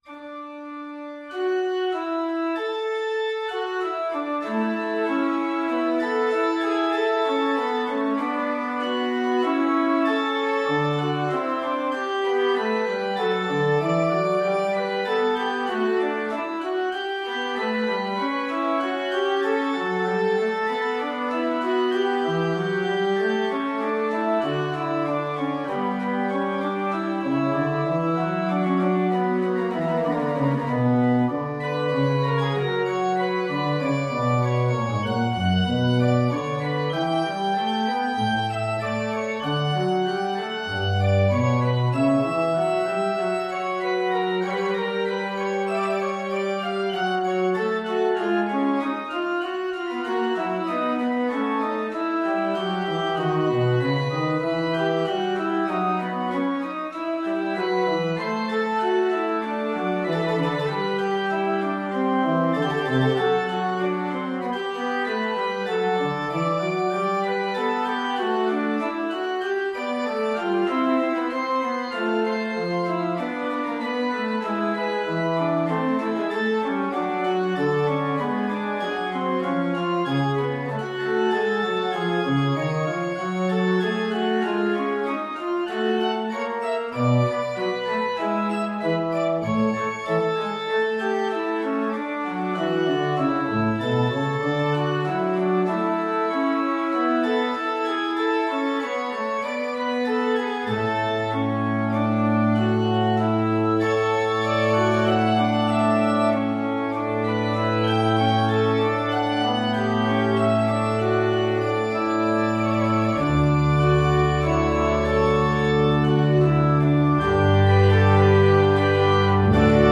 Organ version
Organ  (View more Intermediate Organ Music)
Classical (View more Classical Organ Music)
Audio: MIDI file